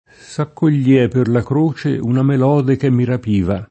melode [mel0de] s. f. (poet. «melodia») — es.: S’accogliea per la croce una melode Che mi rapiva [